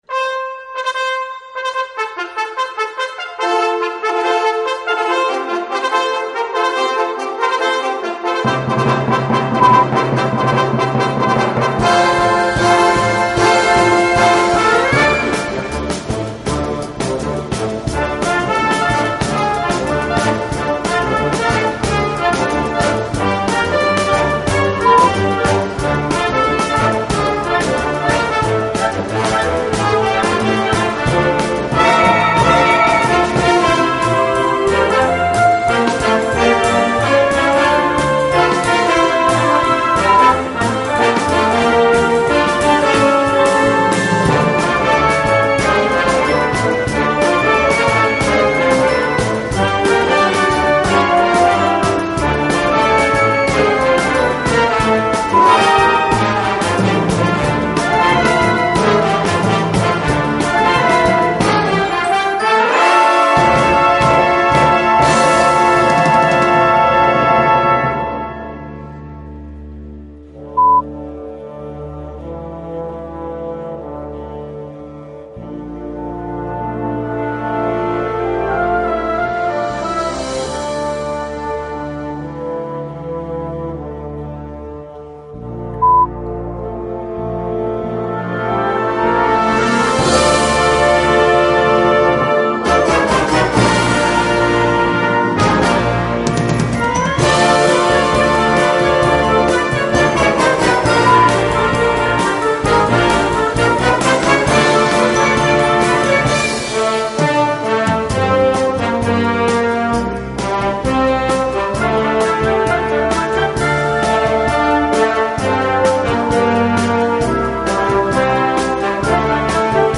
C Besetzung: Blasorchester Tonprobe